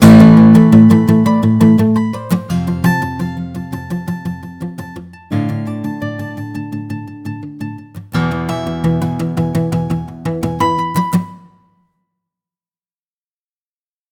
Zunächst ist das Ausganspattern zu hören, danach dann die Variationen, welche mit ChordPotion erzeugt wurden.
Gitarre:
gitarre-midi-1.mp3